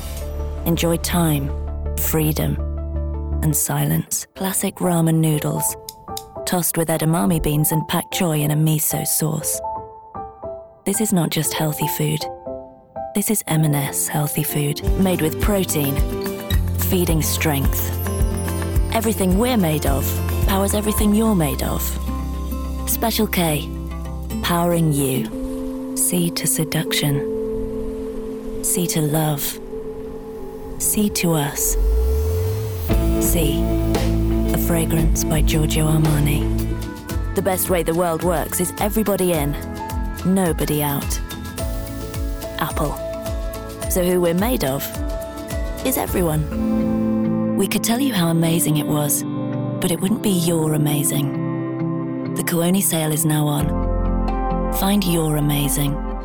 RP ('Received Pronunciation'), Straight
Commercial, Showreel, Narrative